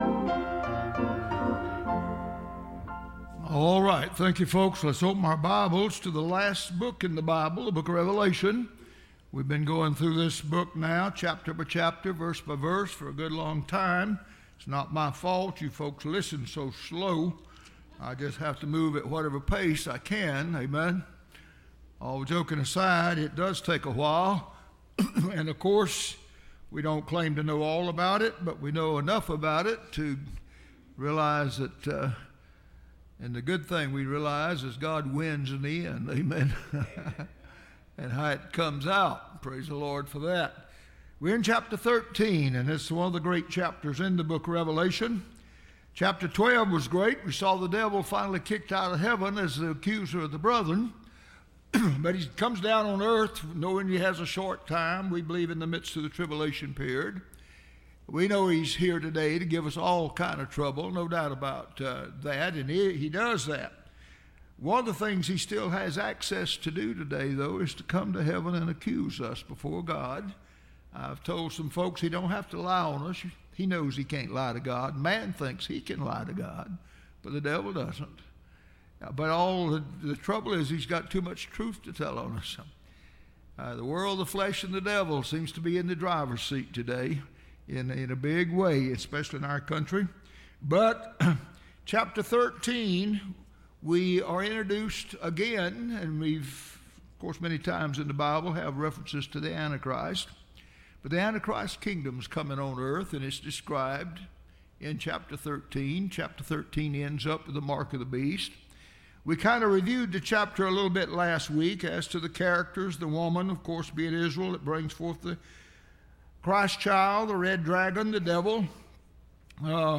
Listen to Message
Service Type: Sunday School